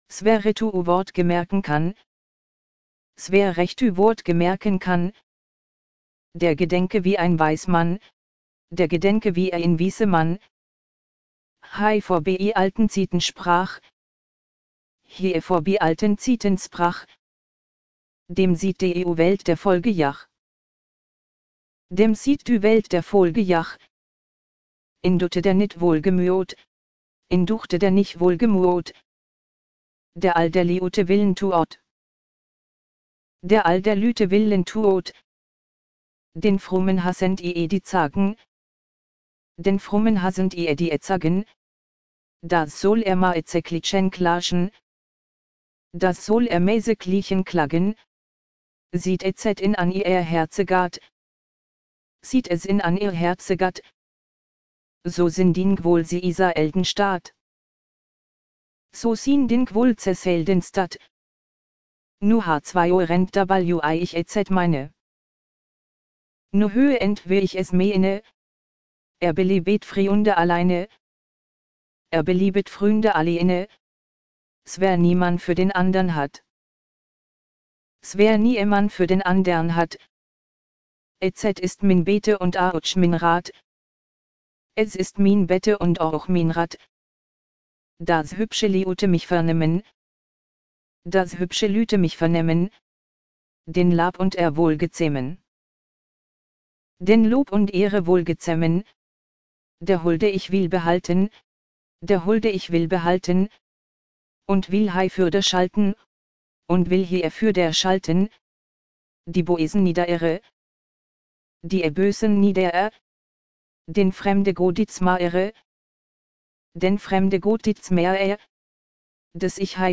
Wir haben in unserem Experiment demonstriert, dass es mit ein paar Zeilen Programmcode, allerdings durchaus möglich ist, aus einem geschriebenen mittelhochdeutschen Text eine verständliche und nachvollziehbare Sprachausgabe zu generieren. Die folgende Datei erlaubt es, die Ausgangslage und das Ergebnis bei Abschluss unseres Projektes zeilenweise zu vergleichen:
Durch die doppelte Lesung ist dies an der obigen Datei nicht ganz so gut erkennbar.